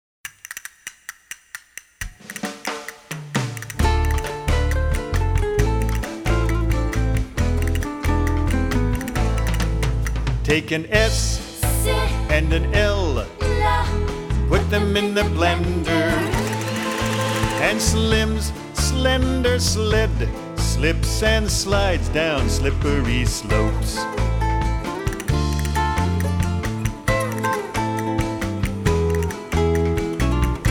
A Reading Song for Teaching Letter Blends